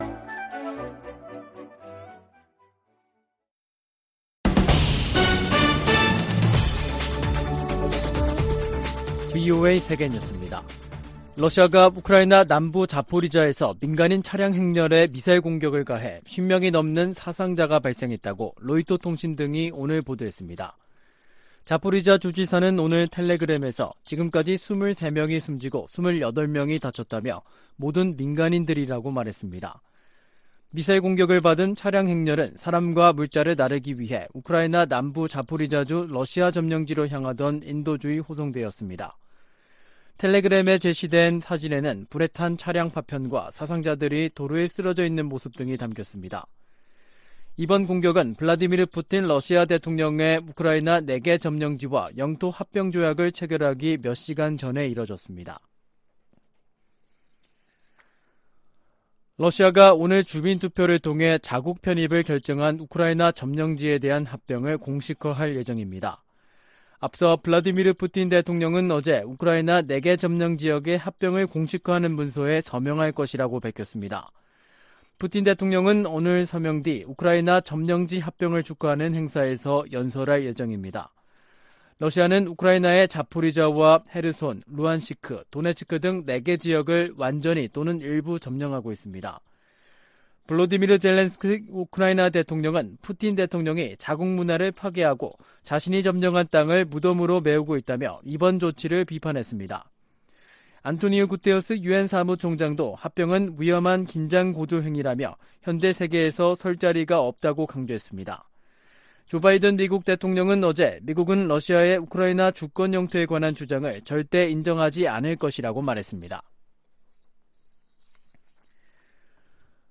VOA 한국어 간판 뉴스 프로그램 '뉴스 투데이', 2022년 9월 30일 3부 방송입니다. 백악관은 카멀라 해리스 부통령이 한국에서 미국의 확장억제 의지를 재확인했다고 밝혔습니다. 북한이 닷새 간 세 차례 탄도미사일 도발을 이어가자 한국 정부는 국제사회와 추가 제재를 검토하겠다고 밝혔습니다. 미한일이 5년만에 연합 대잠수함 훈련을 실시하고 역내 도전에 공동 대응하기로 했습니다.